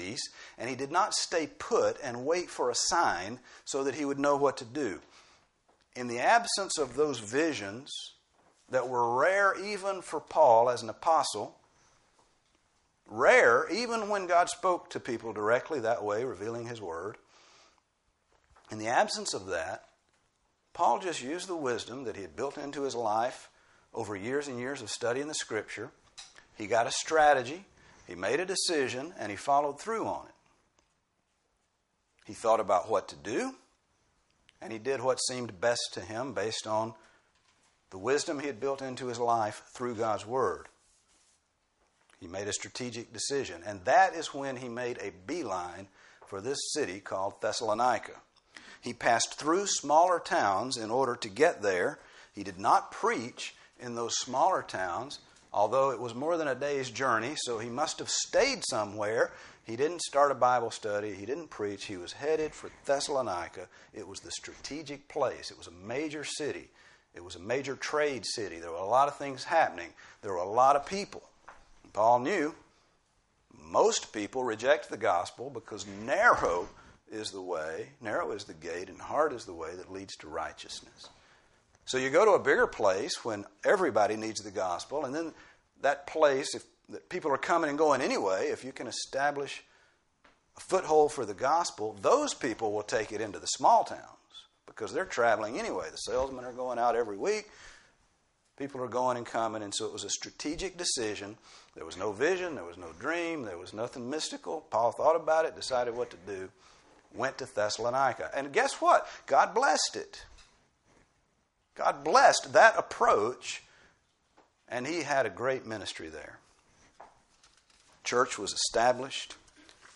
Grace Bible Church - Sermons